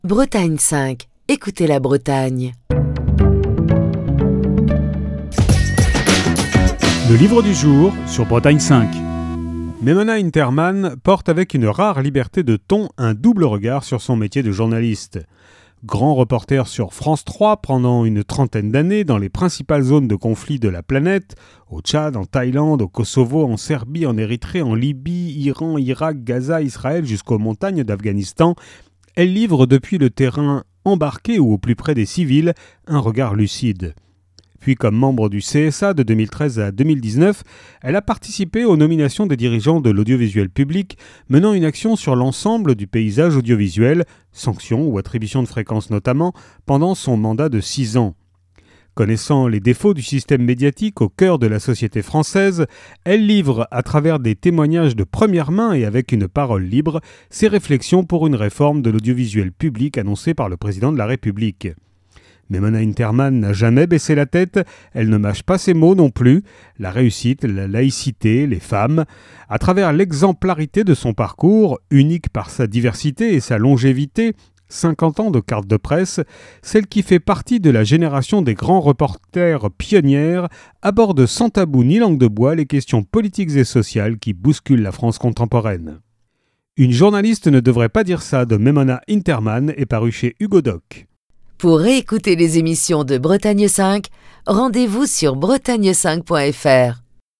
Chronique du 3 mars 2023.